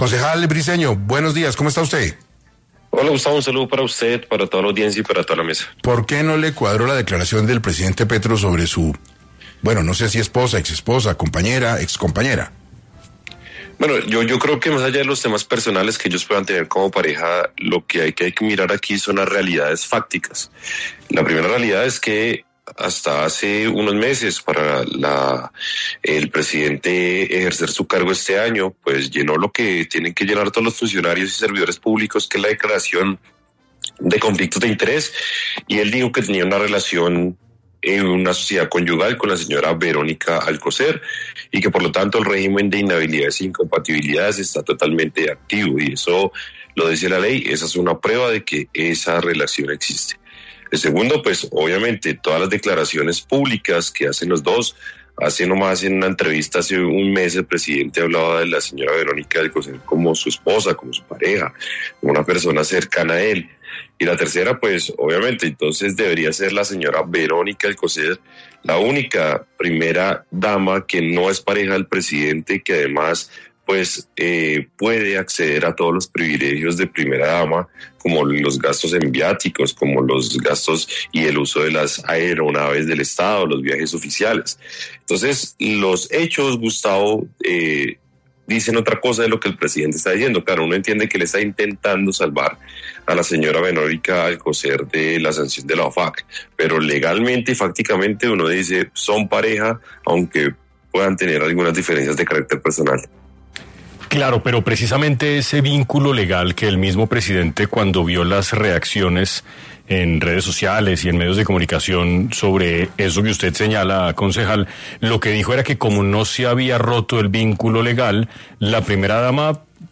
En entrevista con 6AM habló el concejal Daniel Briceño quien denunció este hecho en sus redes sociales donde cuestionó la supuesta separación entre Gustavo Petro y Verónica Alcocer.